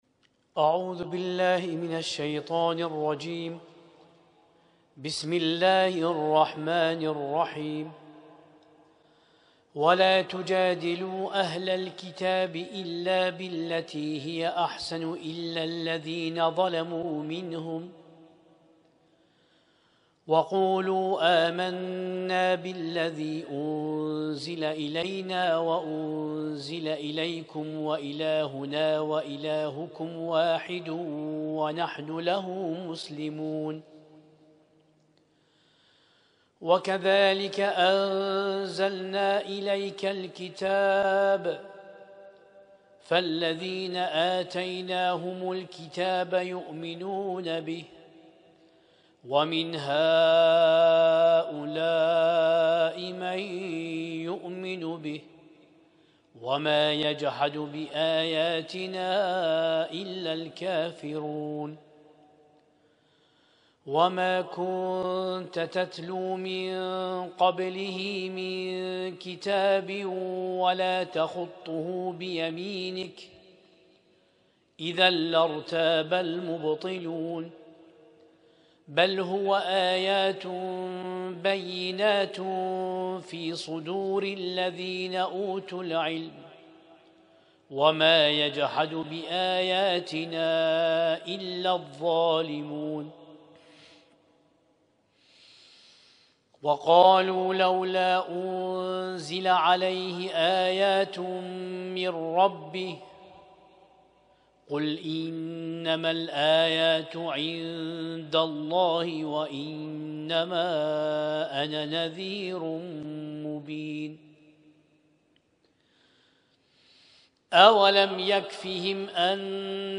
اسم التصنيف: المـكتبة الصــوتيه >> القرآن الكريم >> القرآن الكريم 1447